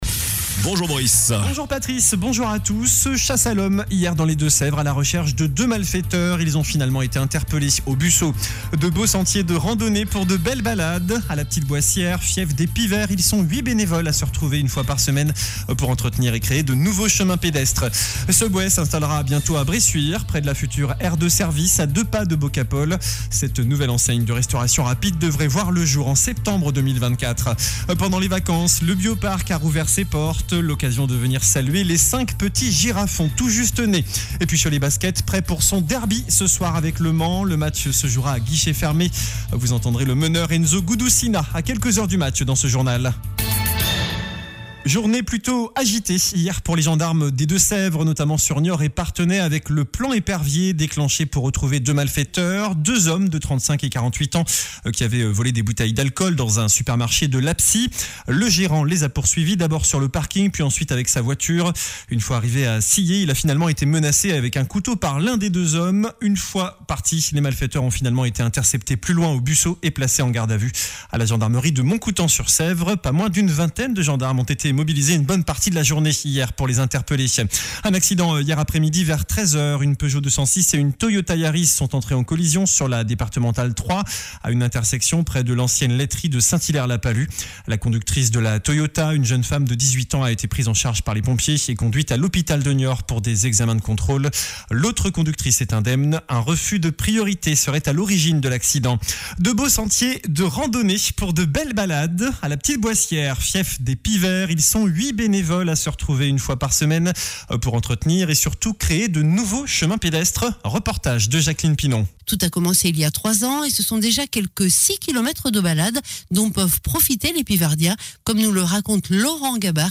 JOURNAL DU MERCREDI 27 DECEMBRE ( MIDI )